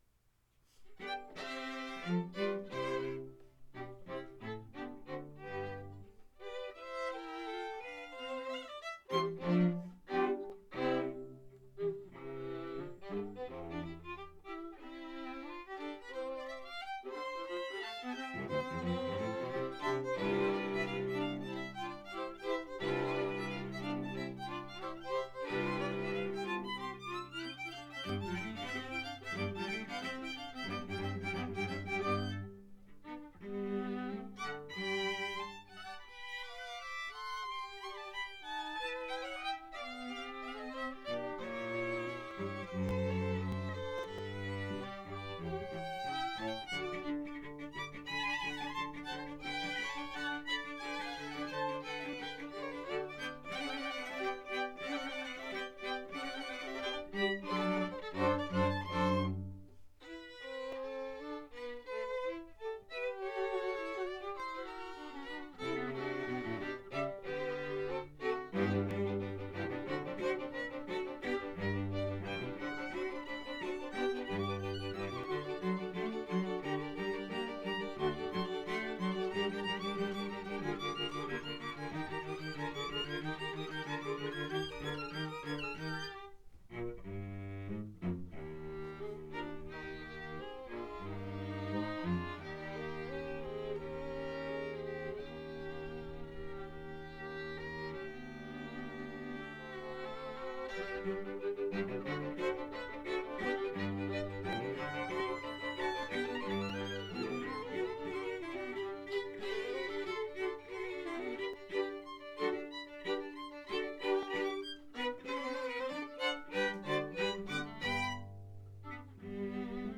the students
Chamber, Choral & Orchestral Music
Chamber Groups
Allegro